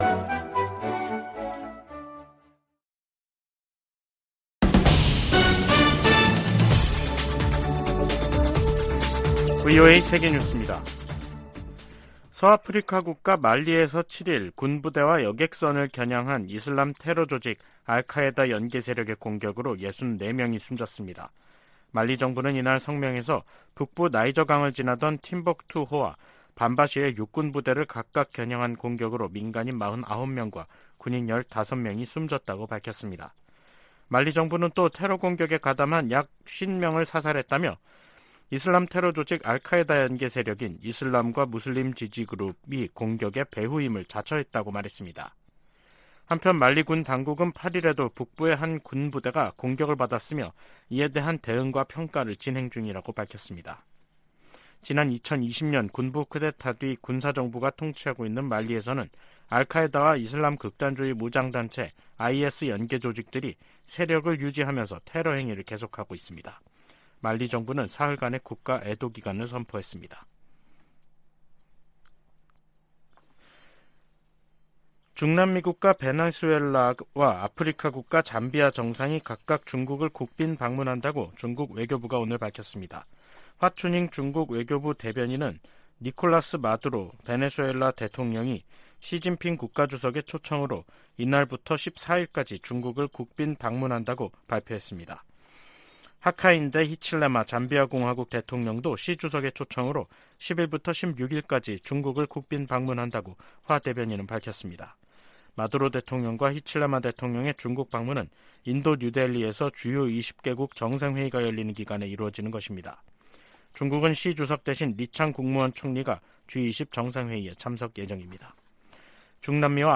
VOA 한국어 간판 뉴스 프로그램 '뉴스 투데이', 2023년 9월 8일 3부 방송입니다. 북한이 수중에서 핵 공격이 가능한 첫 전술 핵공격 잠수함인 '김군옥 영웅함'을 건조했다고 밝혔습니다. 인도네시아에서 열린 동아시아정상회의(EAS)에 참석한 카멀라 해리스 미국 부통령이 북한의 위협적 행동을 강력히 규탄했습니다. 러시아와 무기 거래를 하려는 북한은 '매우 위험한 게임'을 하는 것이라고 미국 상원 외교위원장이 지적했습니다.